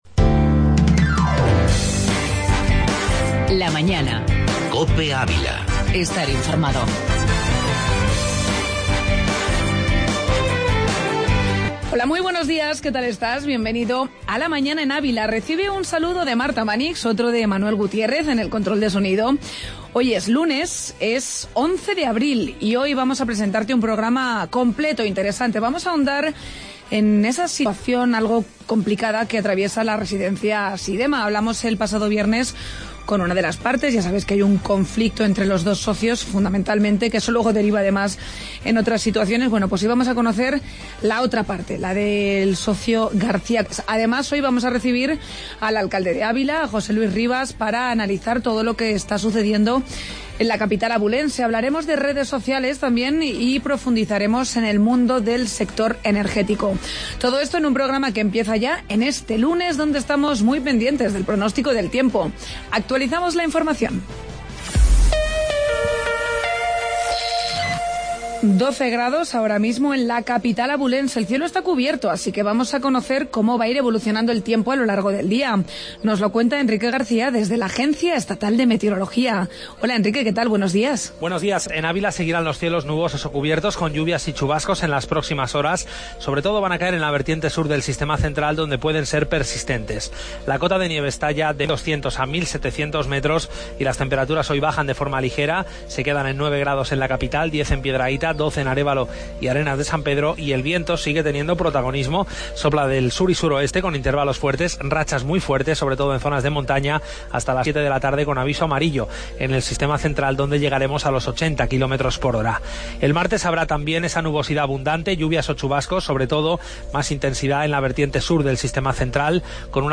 AUDIO: Entrevista Asidema y Espacio ESSE